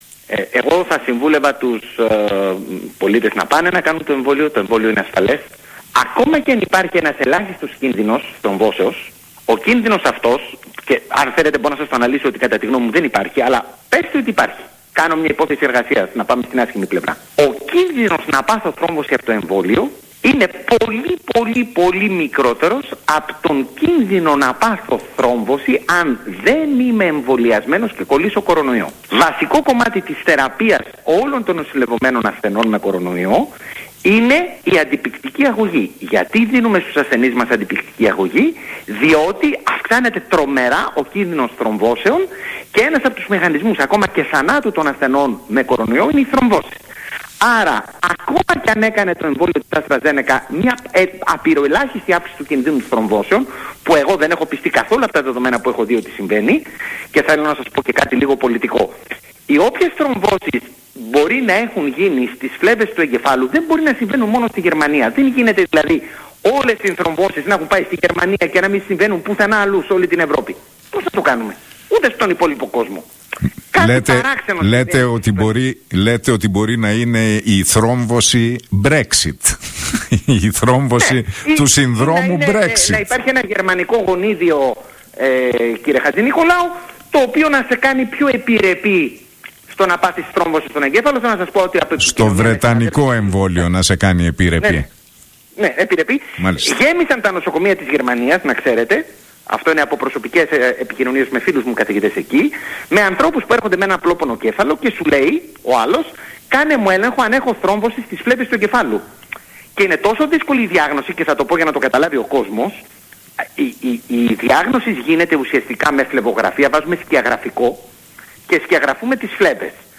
μιλώντας στον realfm 97,8 και στον Νίκο Χατζηνικολάου